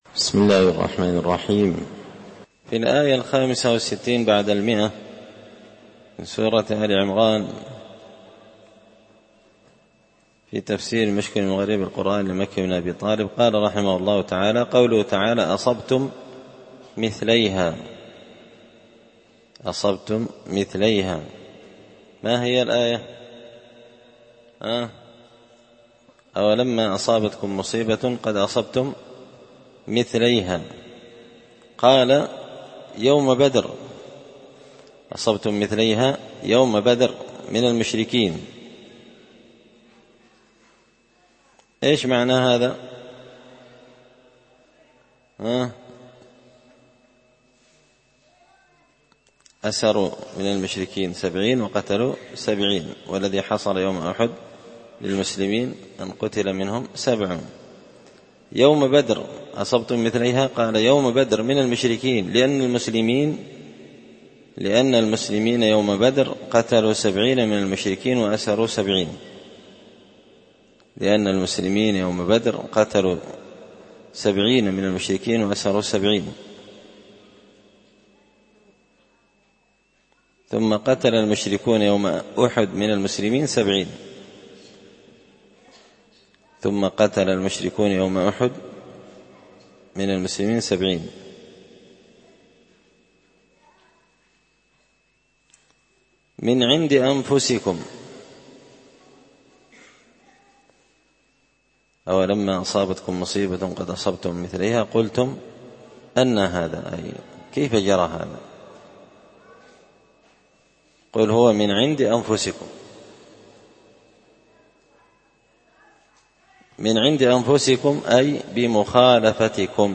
تفسير المشكل من غريب القرآن ـ الدرس 77